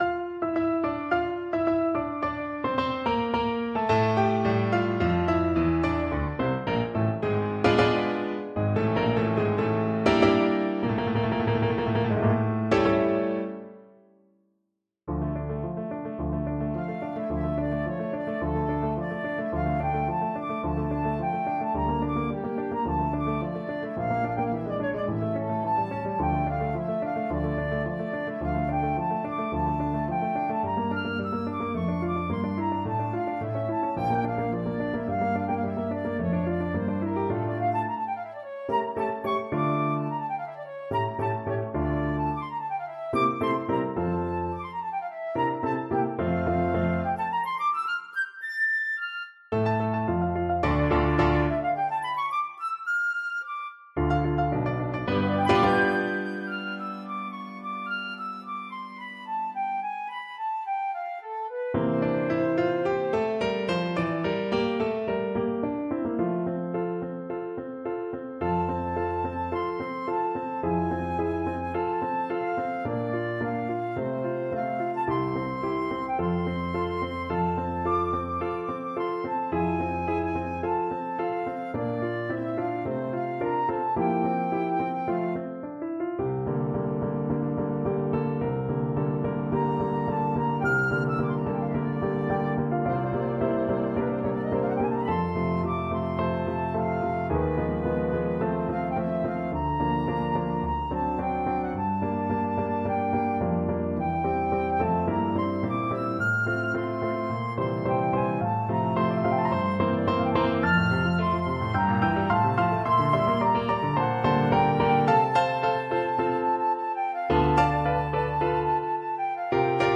Flute version
4/4 (View more 4/4 Music)
Allegro Moderato = 108 (View more music marked Allegro)
Classical (View more Classical Flute Music)